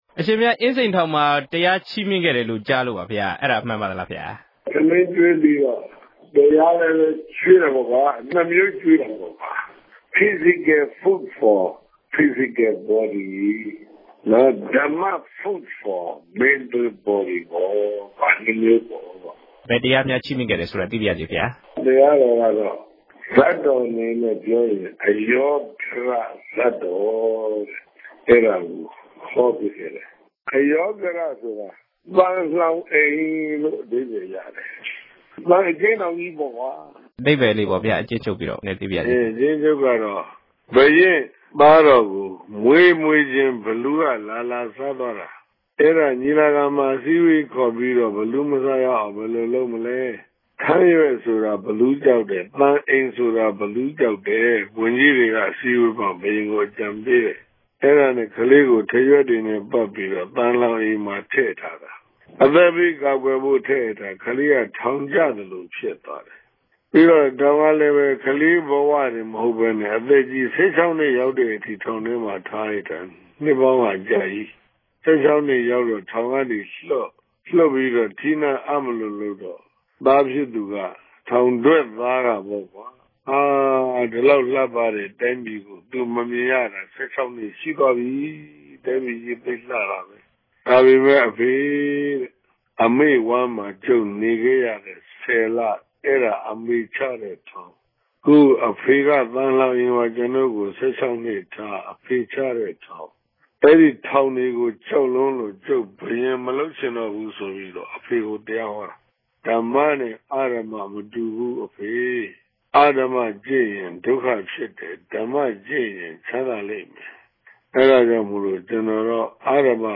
လ္တေွာက်ထားမေးူမန်းခဵက်။